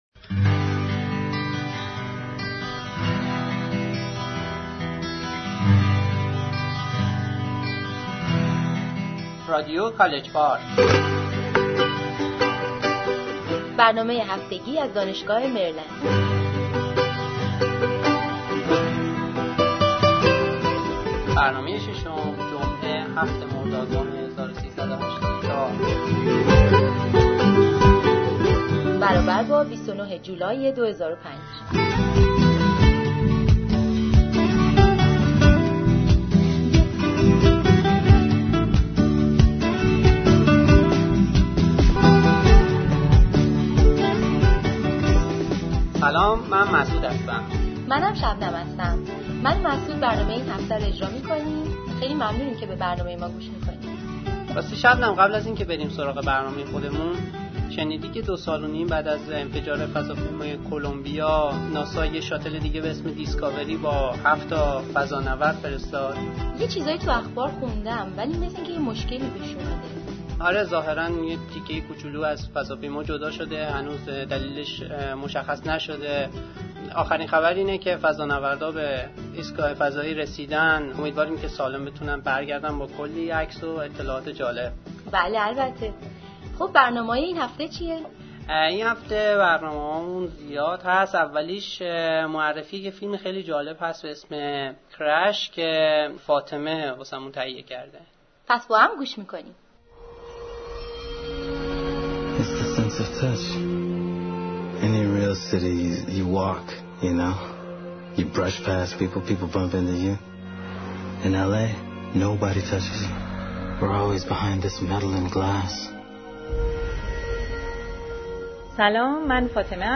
Interview with an Iranian Young Physician in U.S.